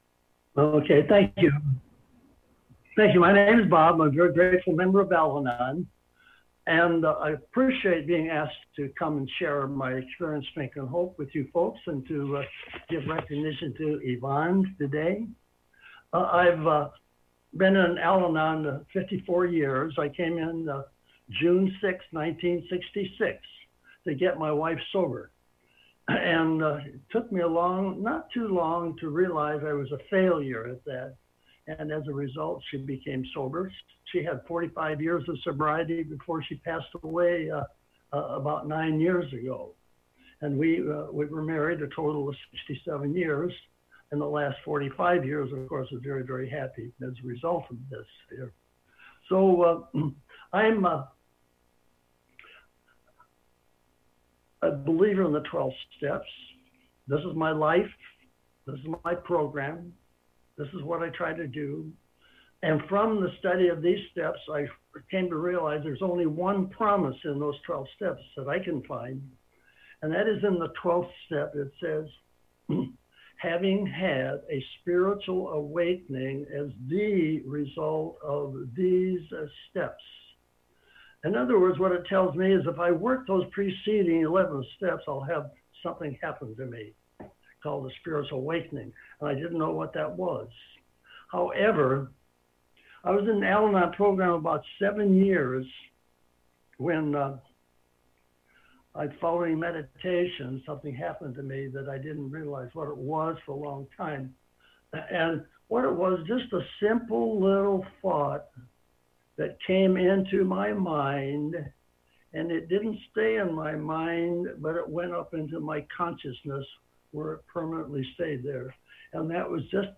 AWB Sunday Special Speaker Meeting